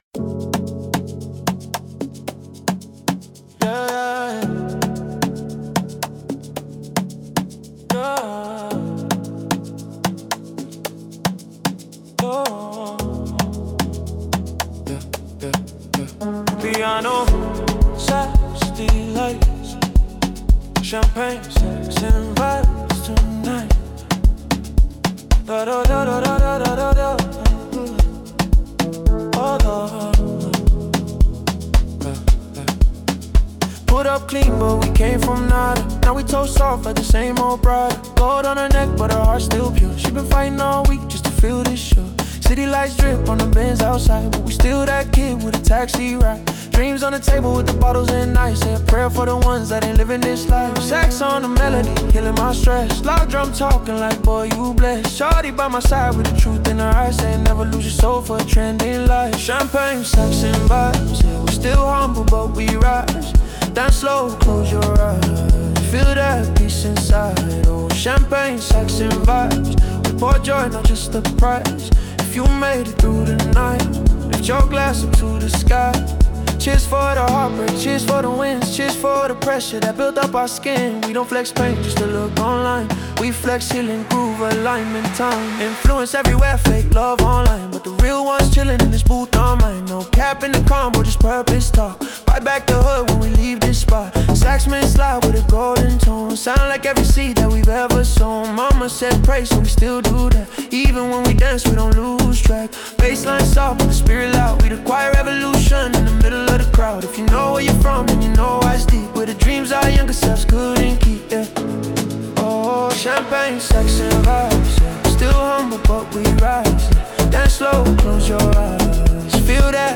Amapiano 2025 Non-Explicit